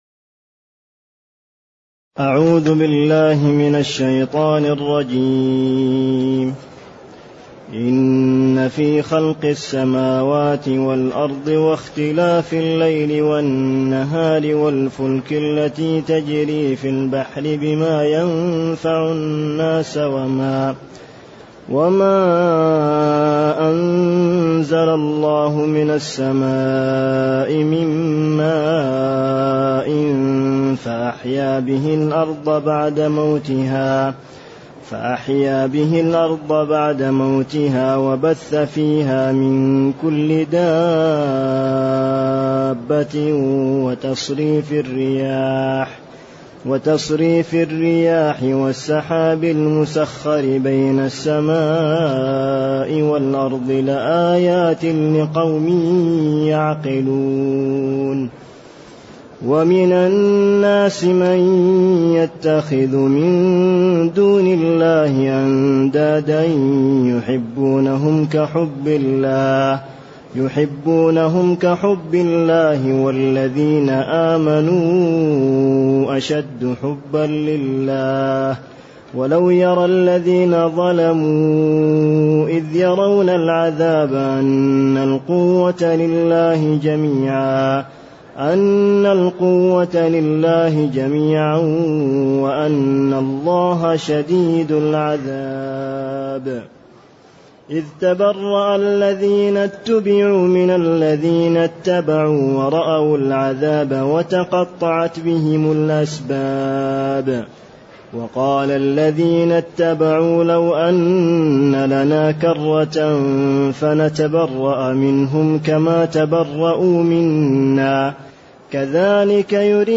تاريخ النشر ١٥ رجب ١٤٢٨ هـ المكان: المسجد النبوي الشيخ